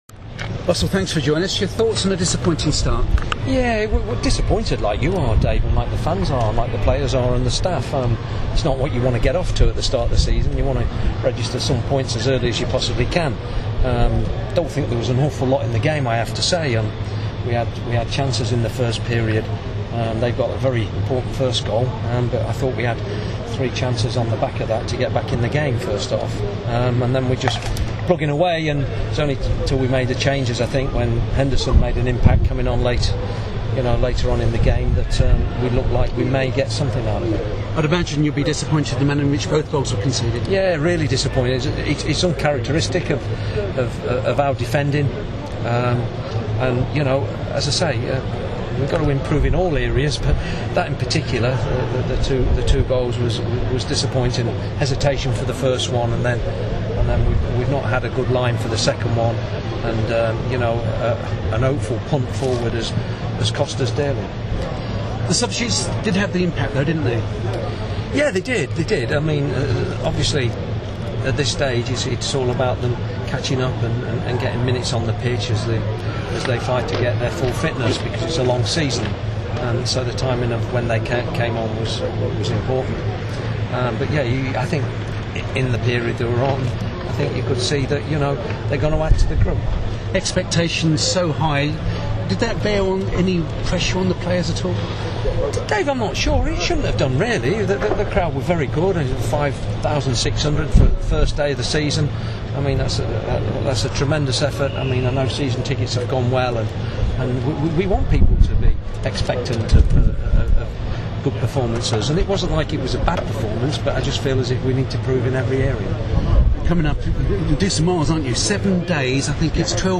Russell Slade, speaking after Orient vs Chesterfield